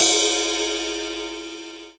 Long_Ride_1.wav